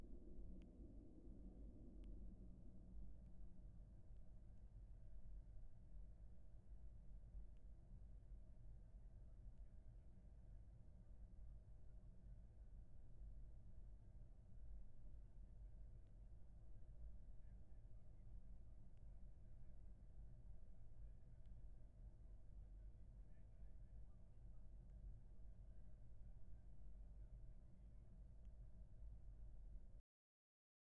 This test uses audio samples taken from three everyday scenarios: a busy street, an office, and an airplane cabin.
airplane-noise.wav